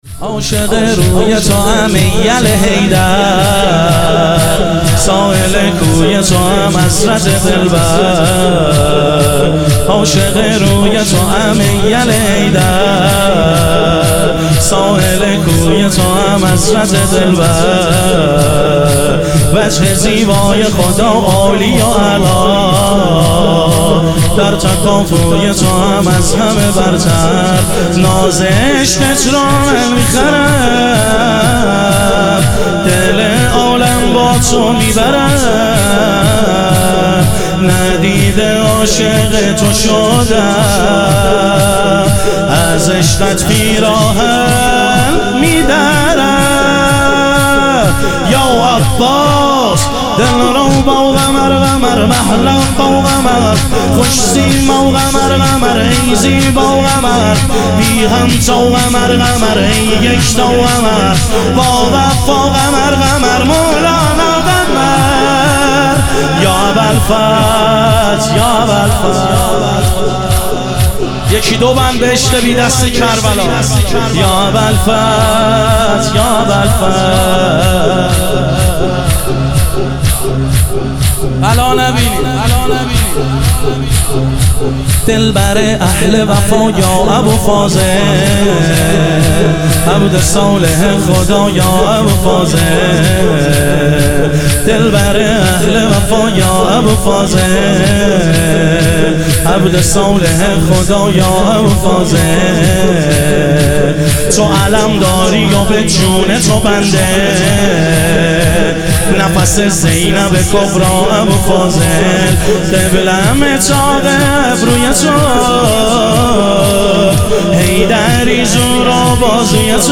شور
شب شهادت حضرت سلطانعلی علیه السلام